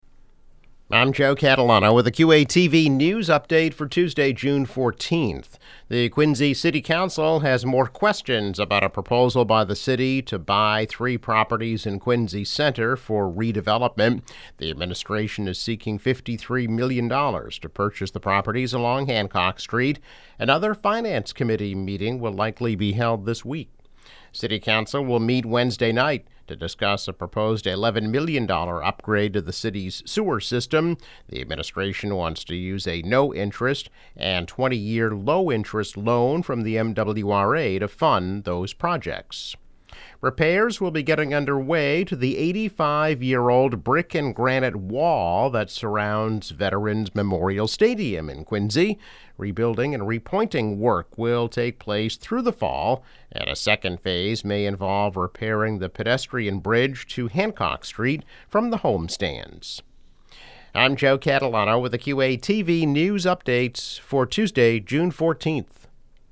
News Update - June 14, 2022